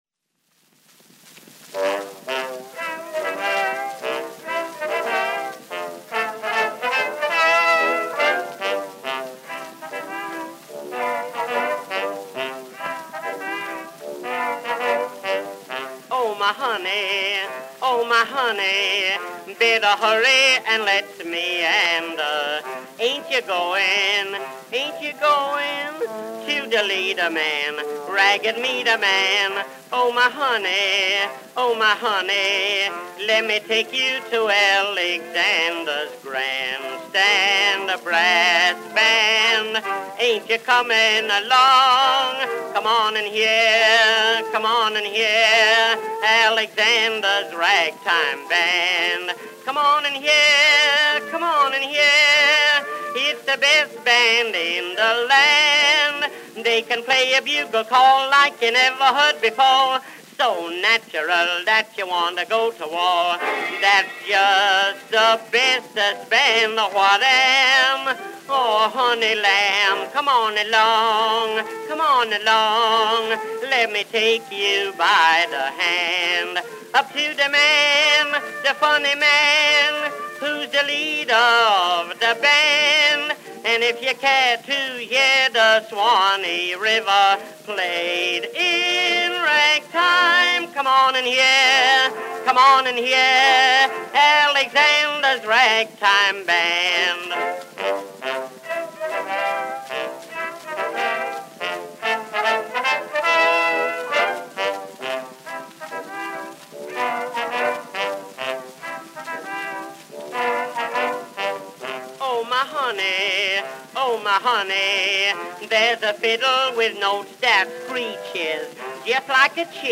Orchestra accompaniment.
Popular music—1911-1920.
Ragtime music.